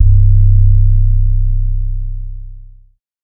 Xizzle [808].wav